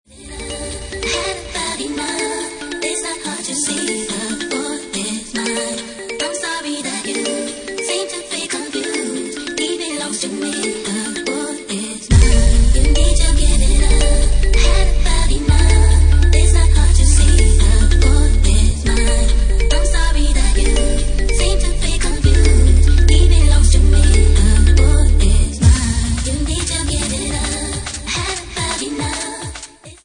Bassline House at 183 bpm